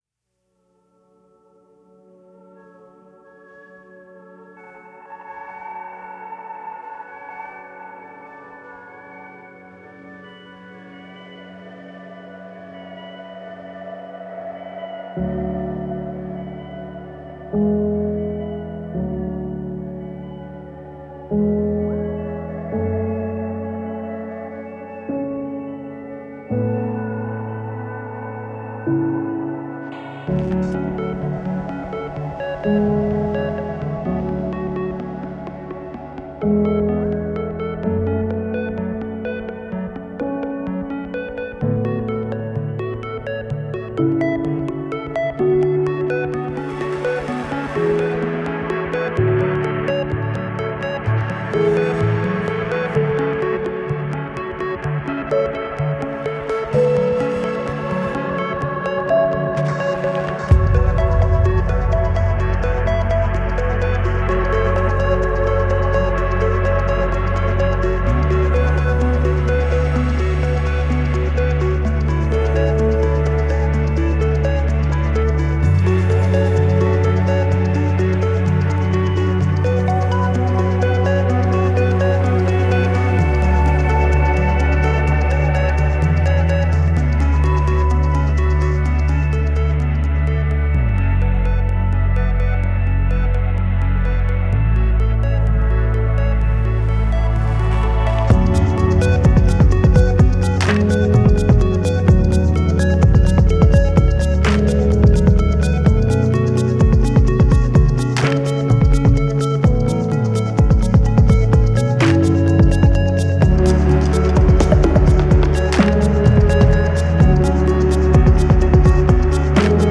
Granular synths, textural crackles and glitchy pulses.